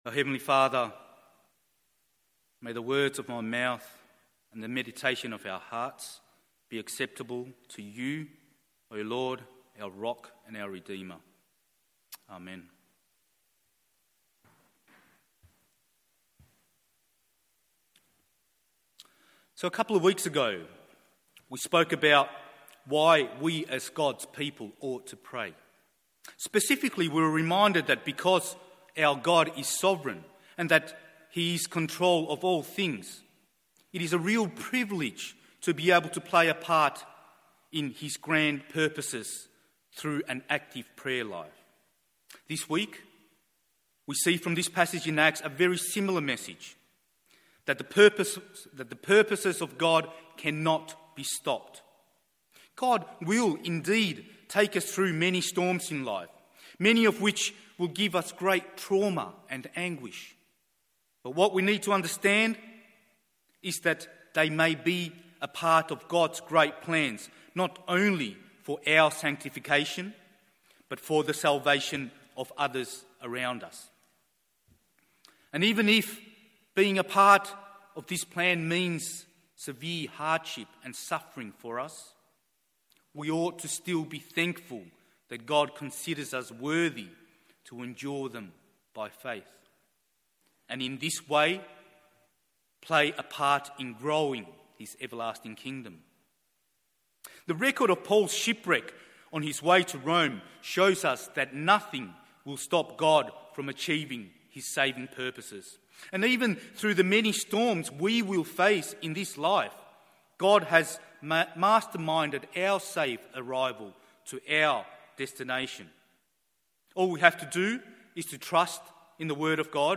MORNING SERVICE Acts 27:13-44…